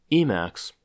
Emacs (/ˈmæks/
En-us-Emacs.oga.mp3